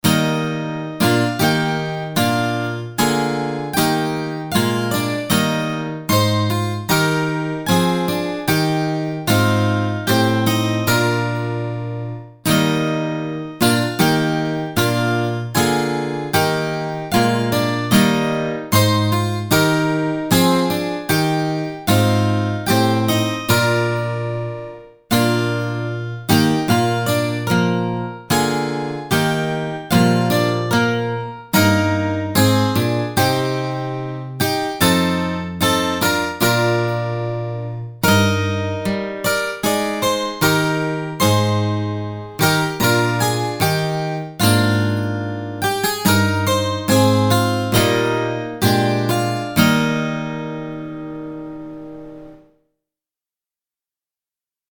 この曲をアコースティックギターで作成。